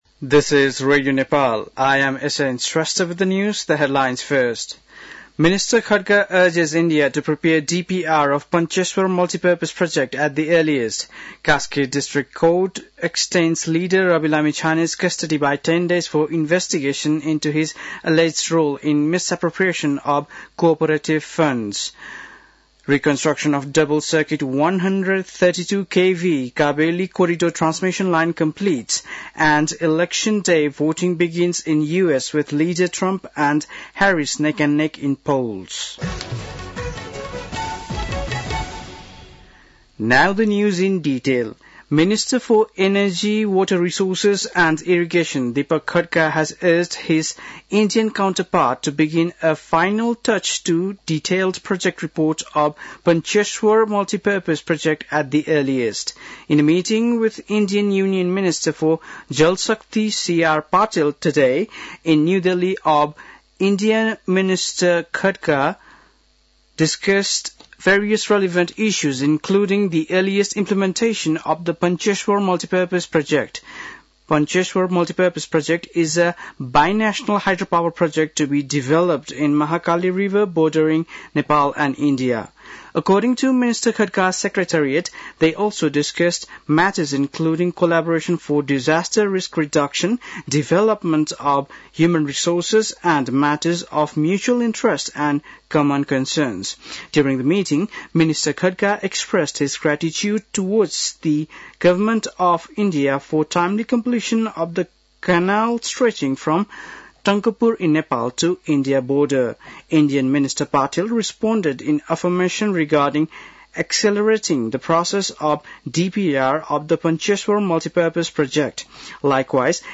An online outlet of Nepal's national radio broadcaster
बेलुकी ८ बजेको अङ्ग्रेजी समाचार : २१ कार्तिक , २०८१